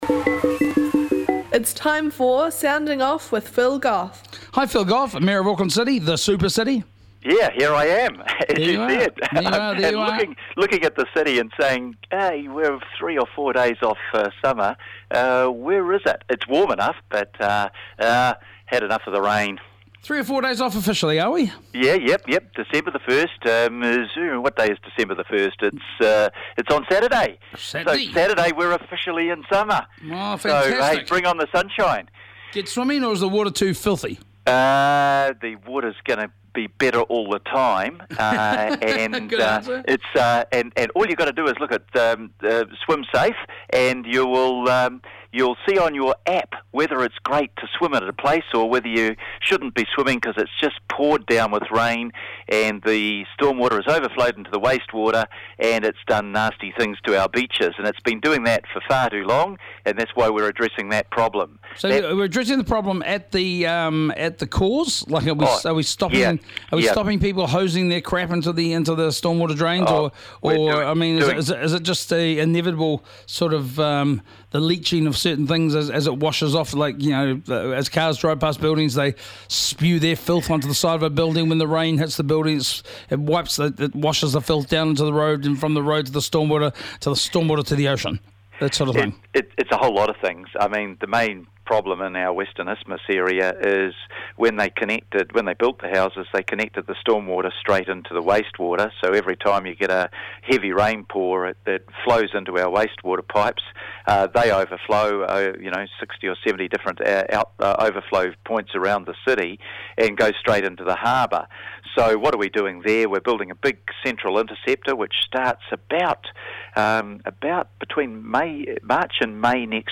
Our weekly catch up with the His Worship the Mayor of Auckland, the Honourable Phil Goff.